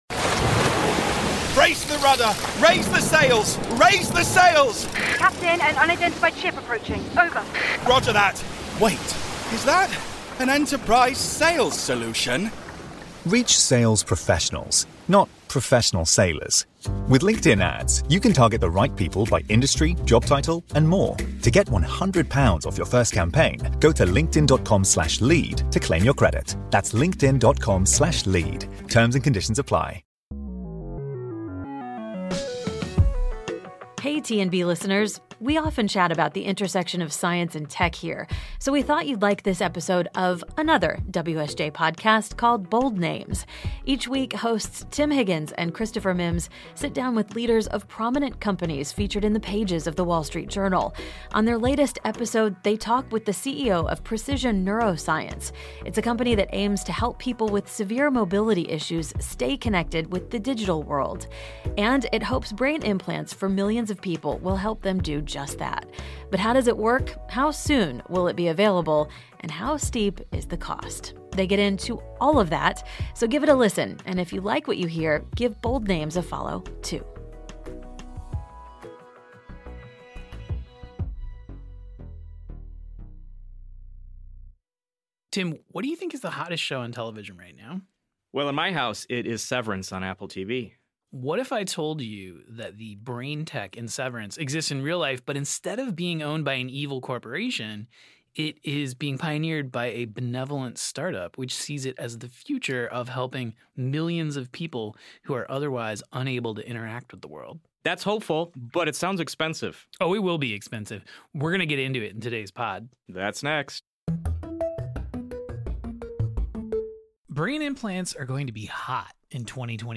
Bonus episode: Today we’re bringing you the latest episode of Bold Names, which presents conversations with the leaders of the bold-named companies featured in the pages of The Wall Street Journal.